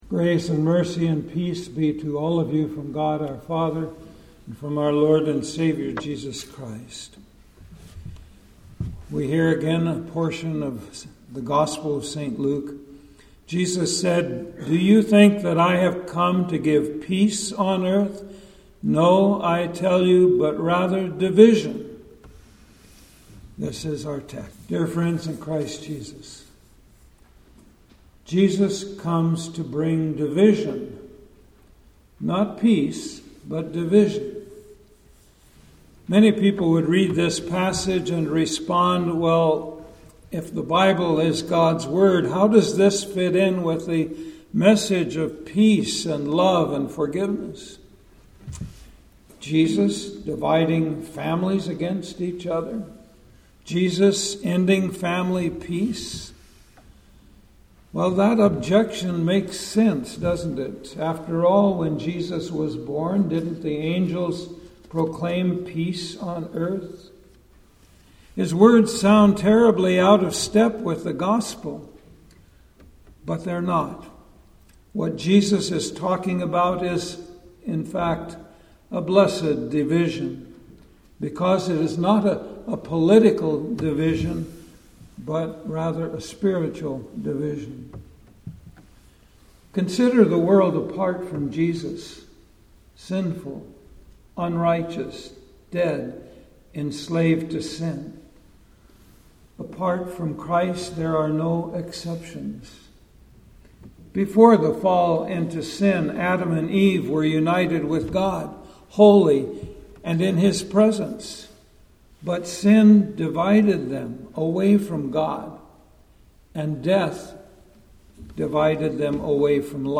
The Tenth Sunday after Pentecost Preacher